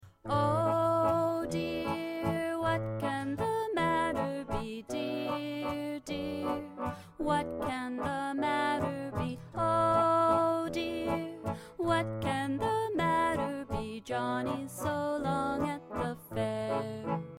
Listen to a sample of this song